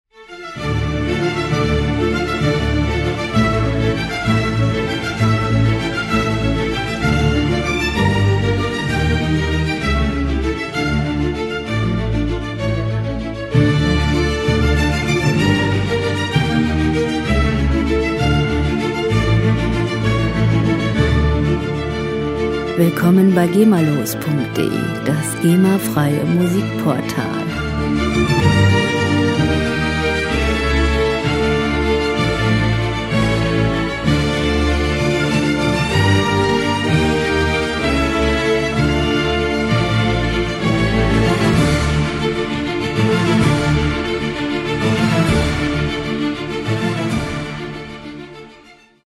• dramatische Orchestermusik
unsere dramatische Orchestrierung des 2. Satzes